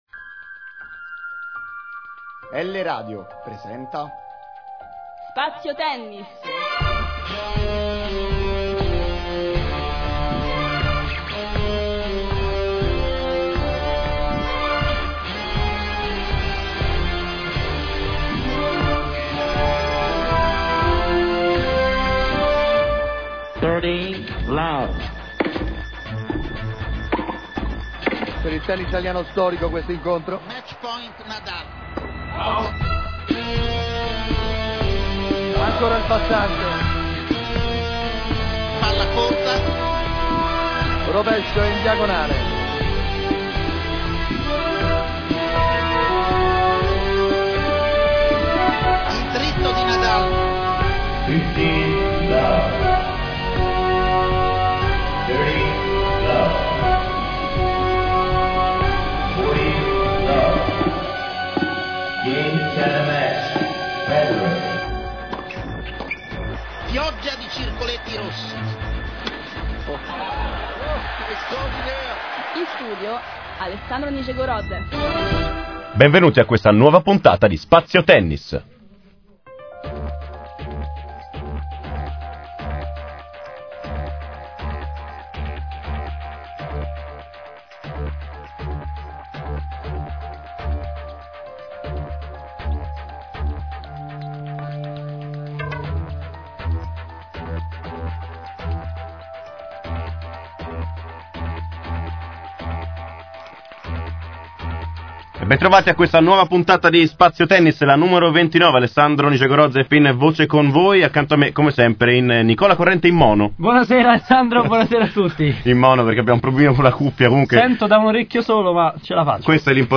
Qui di seguito potete ascoltare la ventinovesima puntata di Spazio Tennis, andata in onda lunedì 22 marzo.
puntata29radio1.mp3